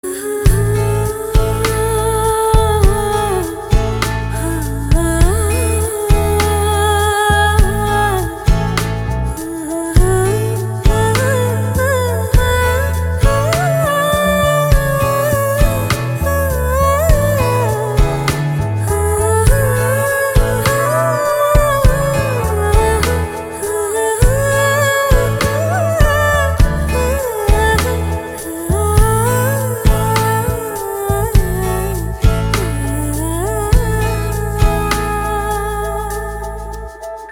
Next magic is about the female voice humming.